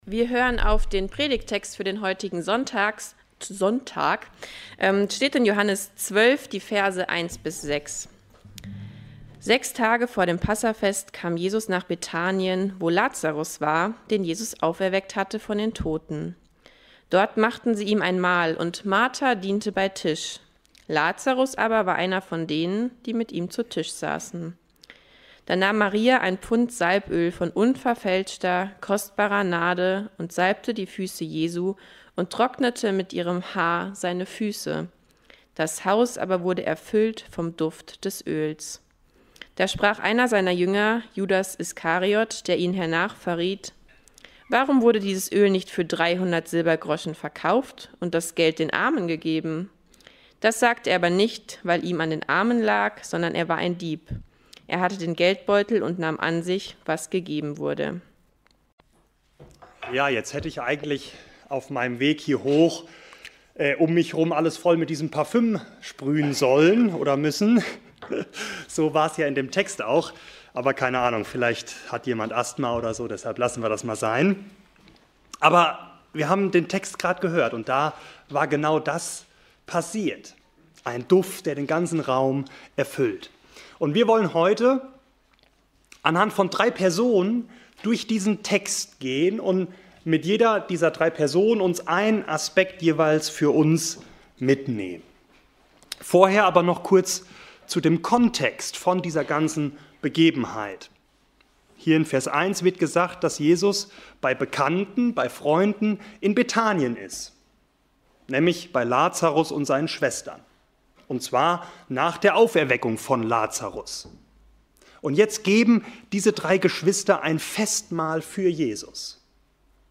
Predigt zum 2. Advent